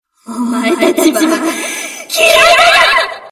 棲艦語音6